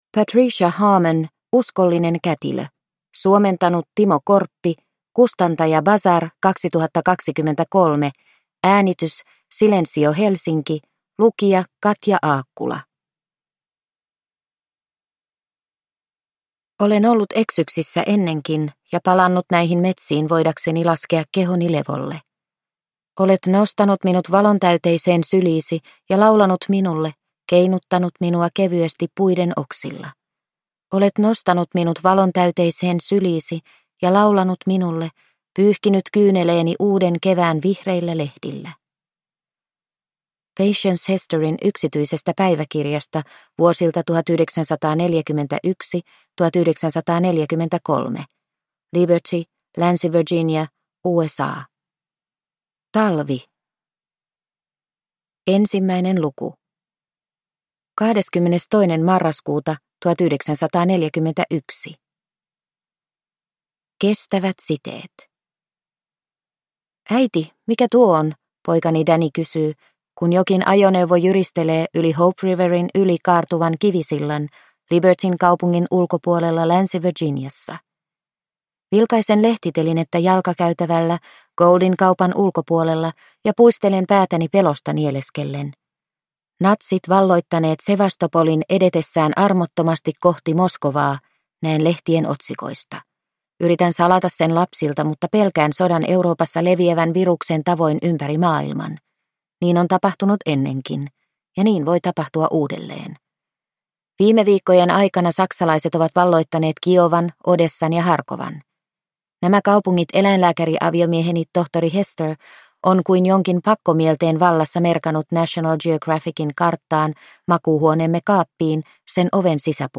Uskollinen kätilö – Ljudbok – Laddas ner